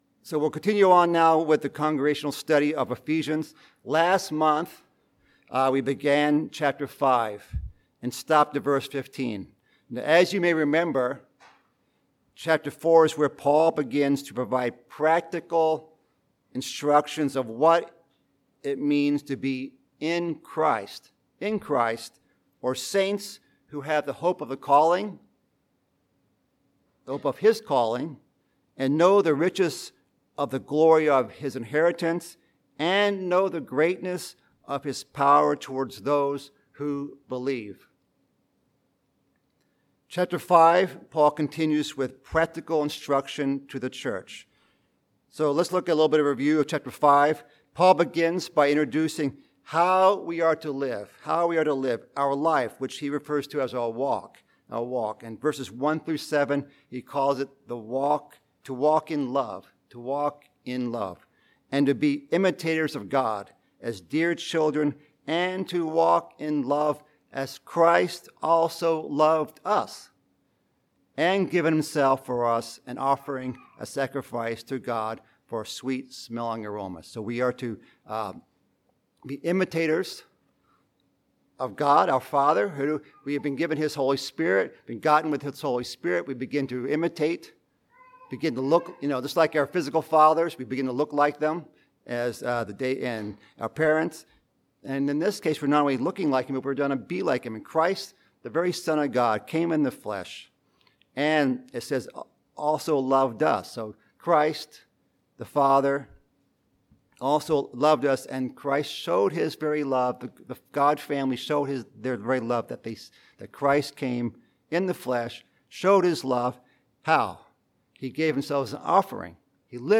Bible Study: Ephesians